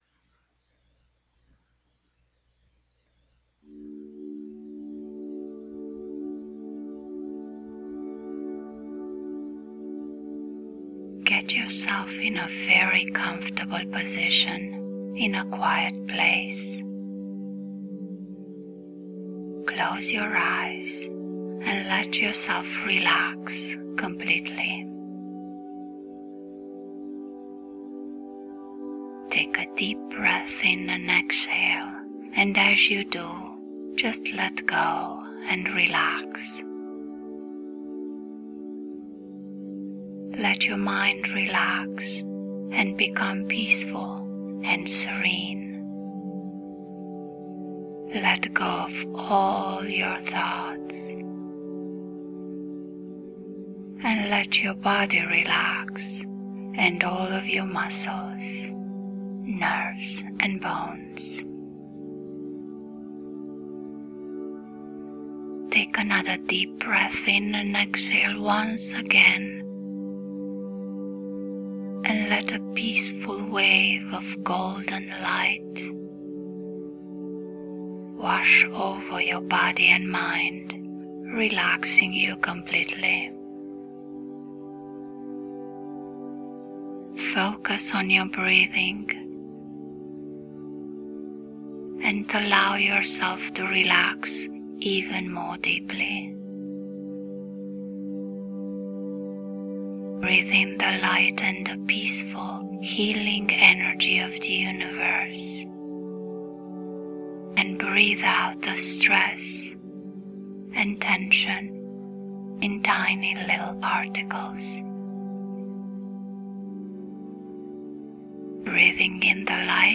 Genre: Guided Meditation Hypnosis.